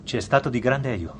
Versione italiana Invece di dire �Grazie dottore, ci � stato di grande aiuto�, il doppiatore dice: �Grazie dottore, ci � stato di grande aj�, come � possibile verificare in questo clip audio [T:28:09].